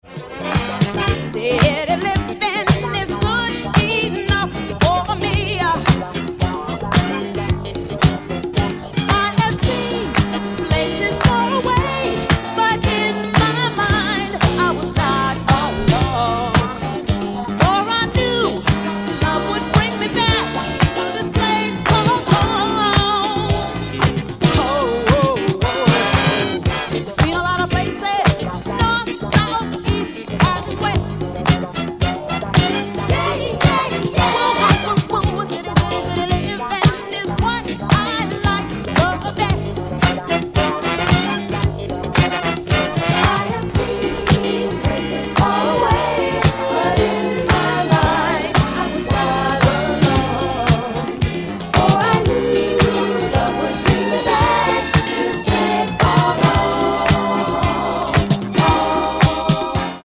A killer bit of modern soul from 1979